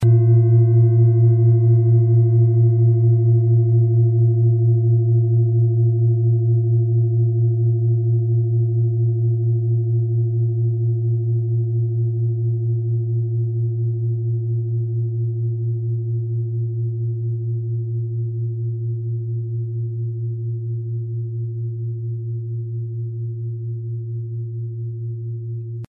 • Tiefster Ton: Mond
PlanetentöneChiron & Mond
MaterialBronze